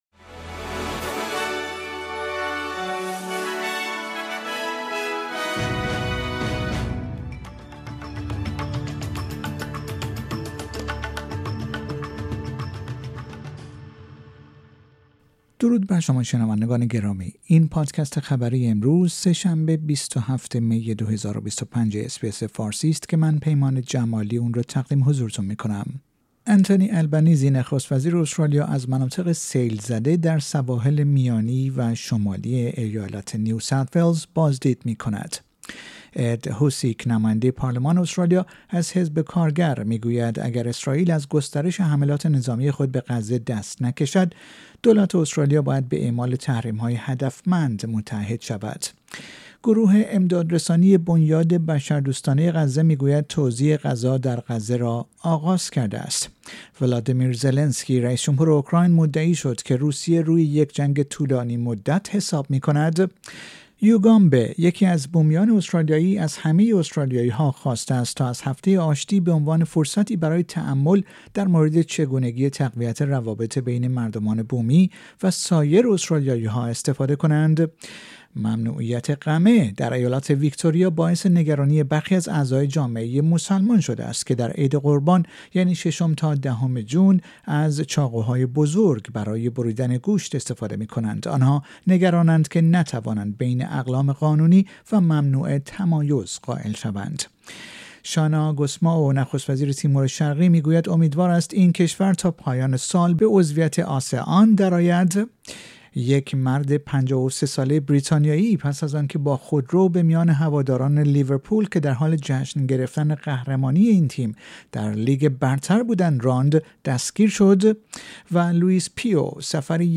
در این پادکست خبری مهمترین اخبار امروز سه شنبه ۲۷ می ارائه شده است.